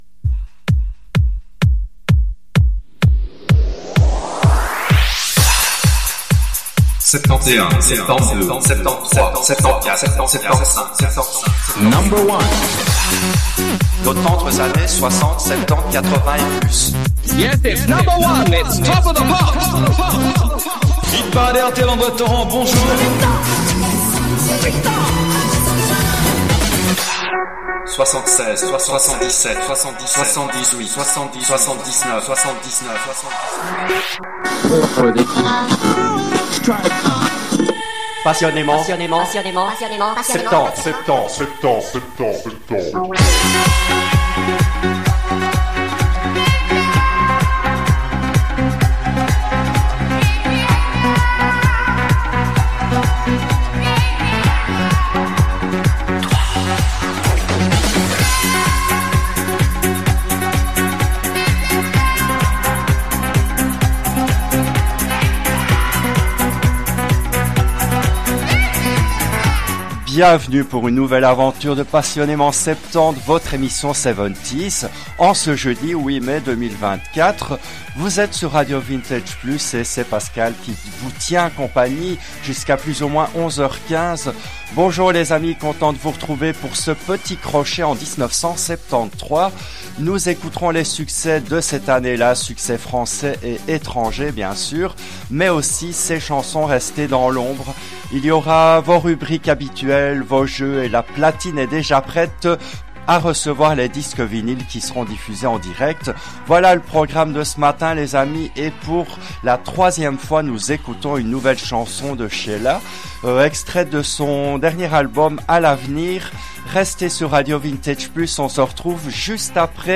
L’émission a été diffusée en direct le jeudi 08 mai 2025 à 10h depuis les studios belges de RADIO RV+.